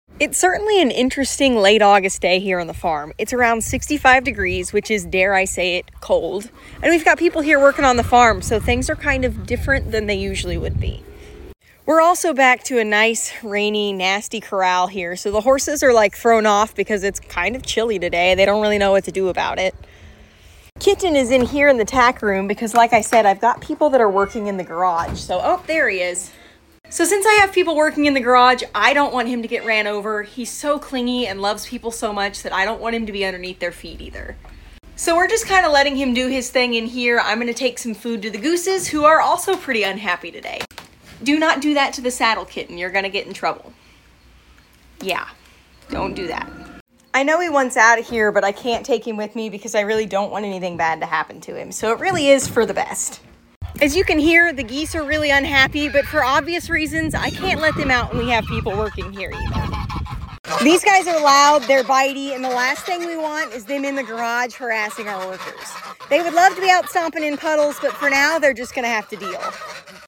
We’ve got workers raising the roof in our garage and some nice cold rain today…